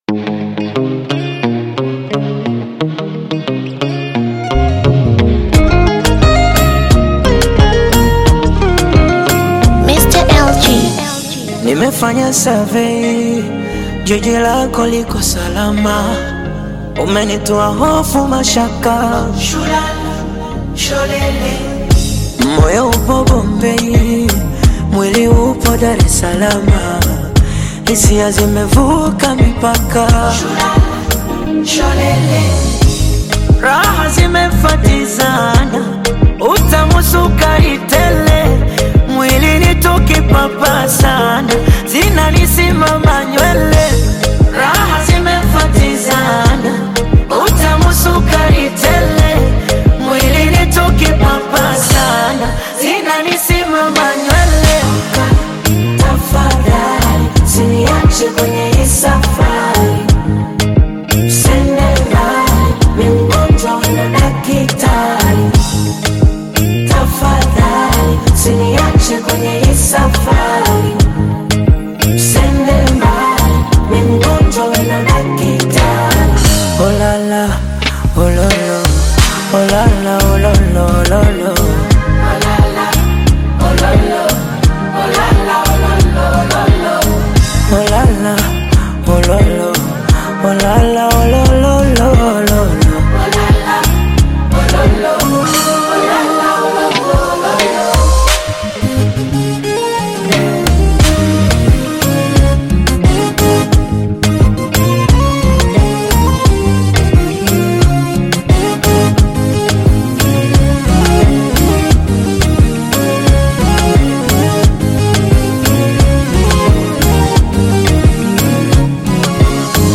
Bongo Flava Love Song music